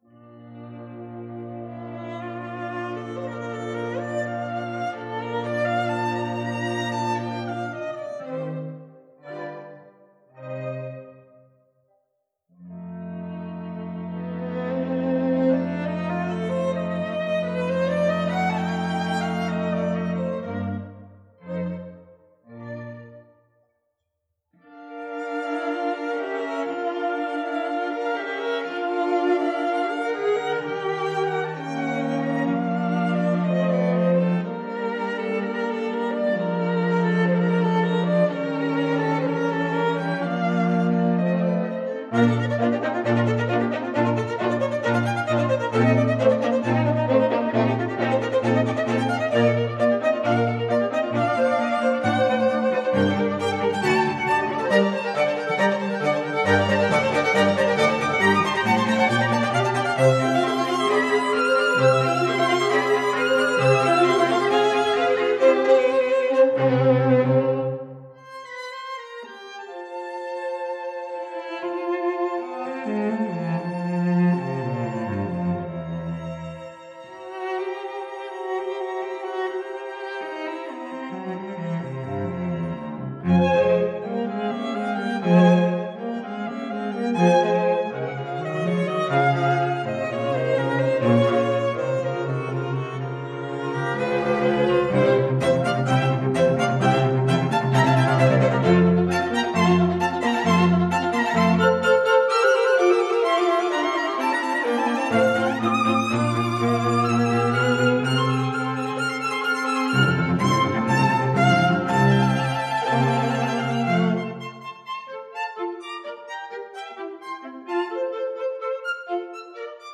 LiveARTS String Quartet